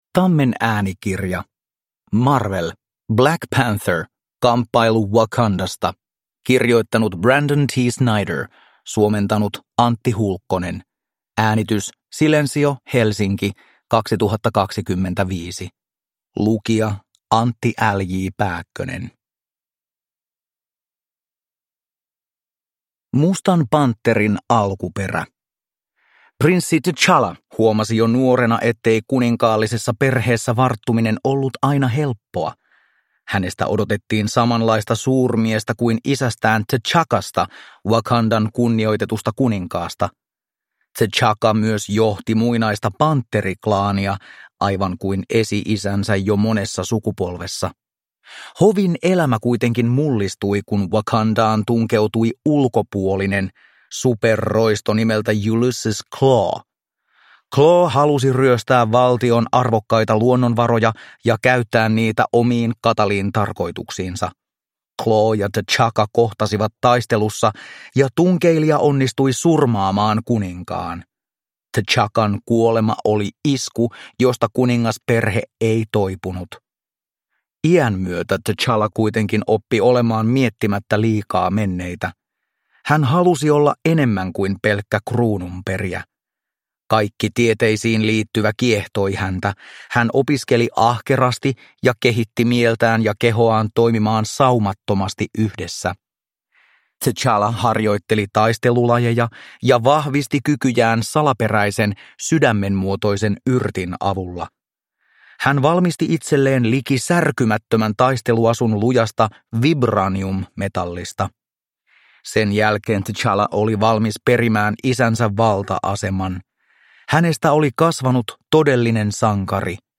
Marvel. Black Panther. Kamppailu Wakandasta – Ljudbok